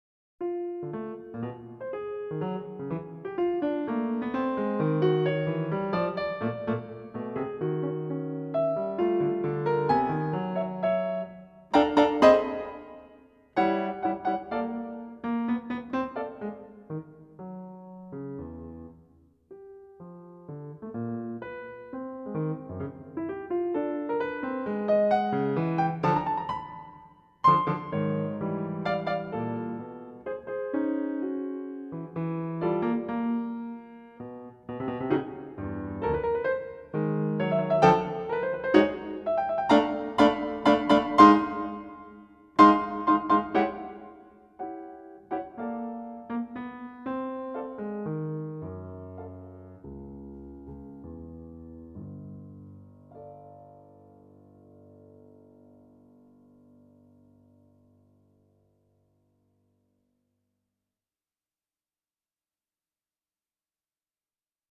SERIALISM: